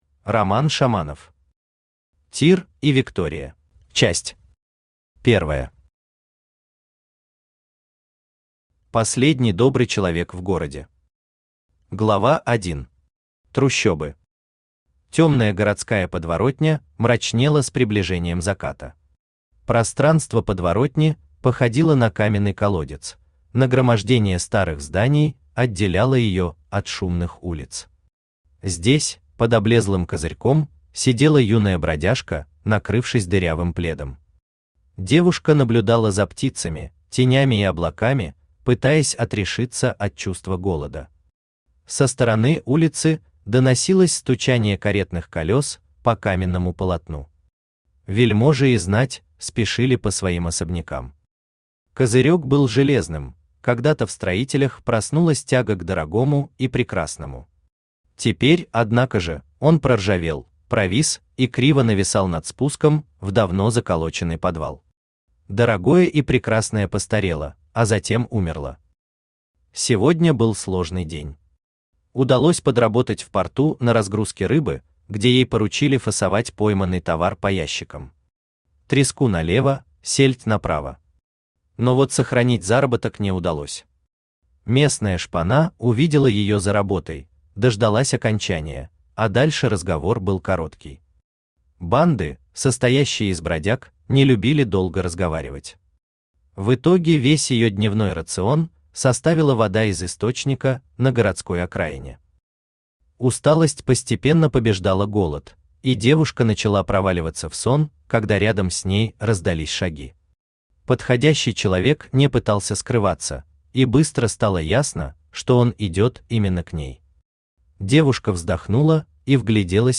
Аудиокнига Тир и Виктория | Библиотека аудиокниг
Aудиокнига Тир и Виктория Автор Роман Шаманов Читает аудиокнигу Авточтец ЛитРес.